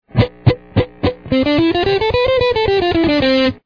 C Major Scale on the Guitar
Play Scale Slow |